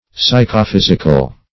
Psychophysical \Psy`cho*phys"ic*al\, a.